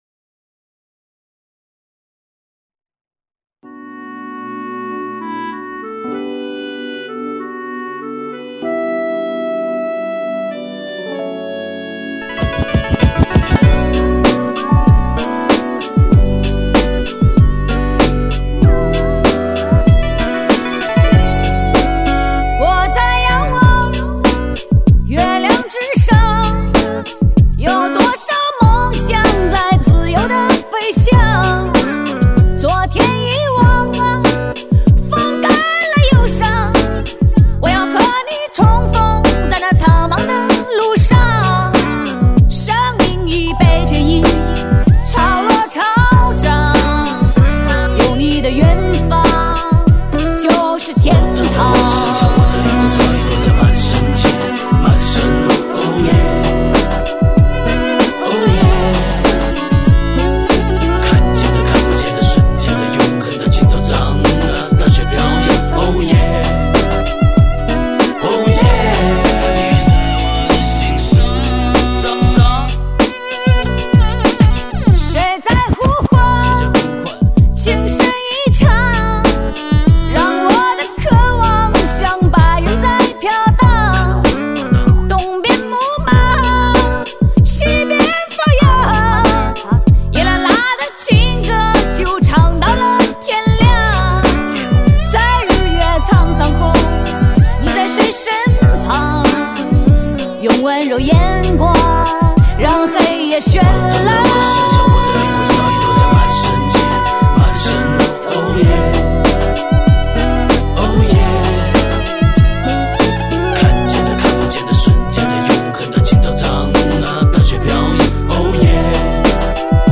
不错  女声很有爆发力